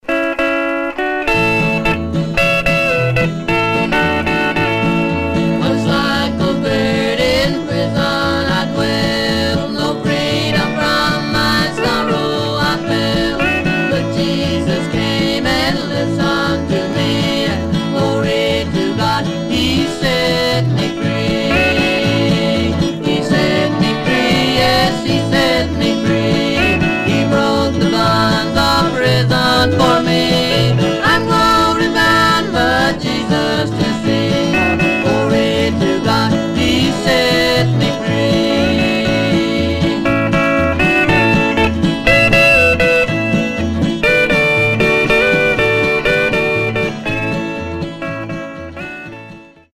Stereo/mono Mono
Country